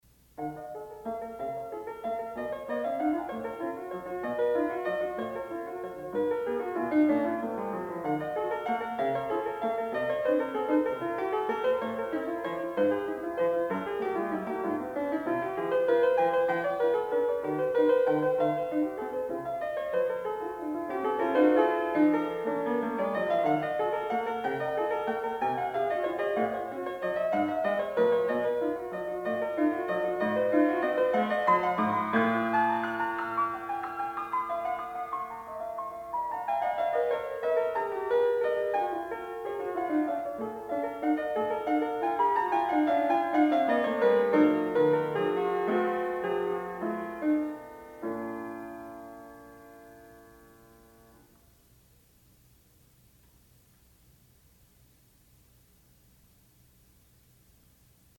Graduate Recital
07 Prelude.mp3